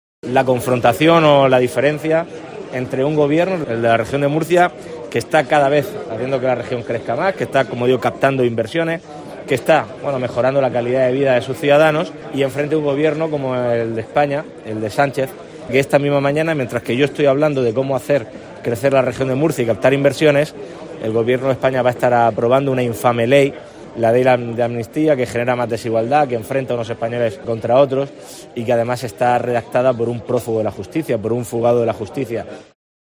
El presidente de la Comunidad, Fernando López Miras, expuso hoy que la Región de Murcia es actualmente “uno de los lugares más atractivos y fiables de España para invertir”, con “infinitas posibilidades”. López Miras se refirió al potencial de la Región para atraer capital durante el foro ‘Región de Murcia, destino inversor’, organizado en Madrid por el diario El Confidencial.